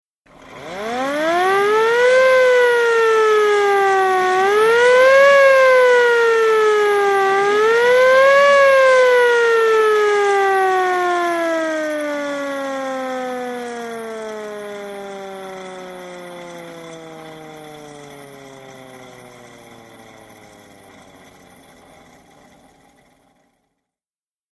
Antique|Sirens | Sneak On The Lot
VEHICLES - HORNS ANTIQUE: Hand cranked siren, antique fire engine.